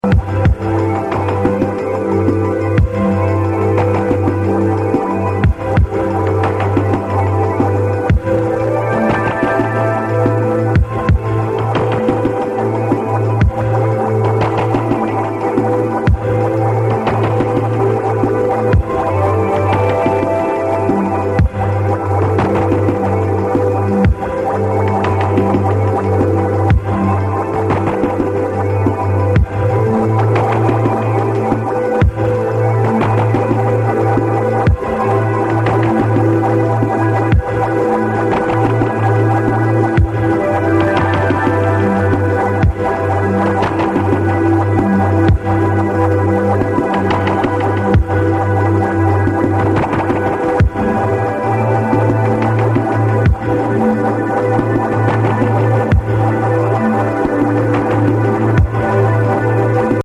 suspenseful and gloomy